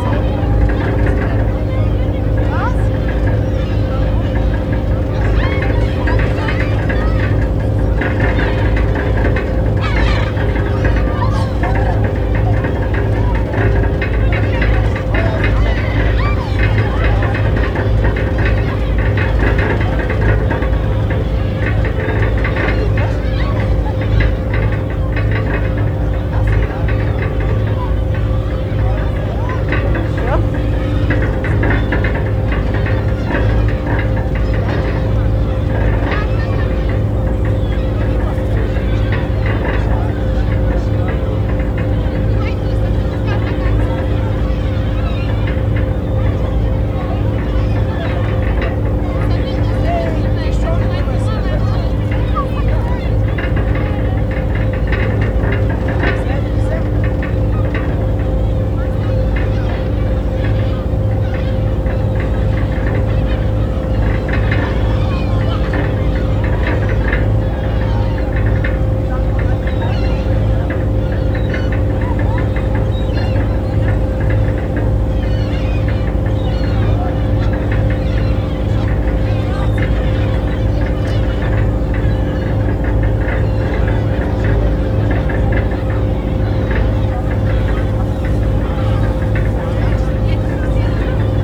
Directory Listing of /_MP3/allathangok/termeszetben/sargalabusiraly_standardt/
akompfedelzeterol_marantz01.31.WAV